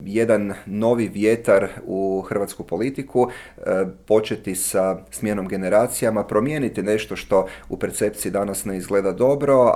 ZAGREB - Međimurski župan Matija Posavec, nositelj HNS-ove liste za europske izbore, u razgovoru za Media servis otkrio je zašto ustraje na samostalnom izlasku, predstavlja li HNS-u vodstvo uteg, planira li zasjesti na mjesto predsjednika stranke i zašto nije bio za ulazak u vladajuću koaliciju.